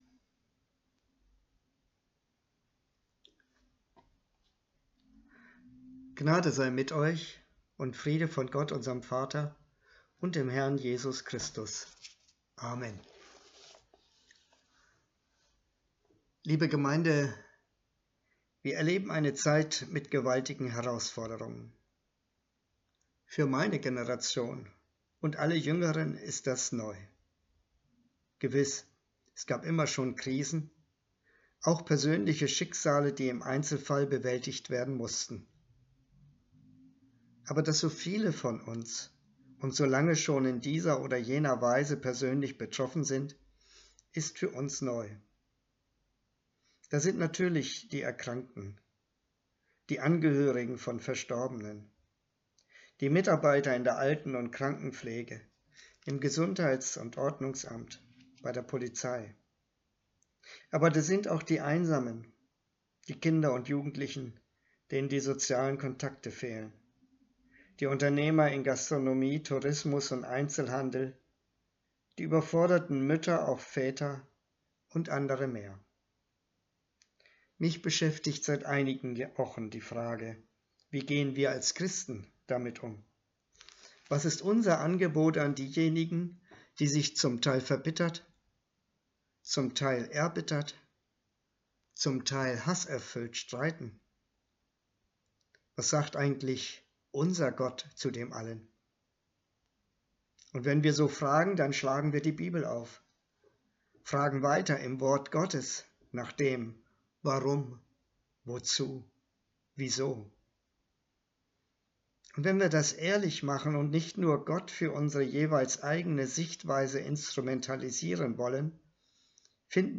02.05.2021 – Gottesdienst
Predigt und Aufzeichnungen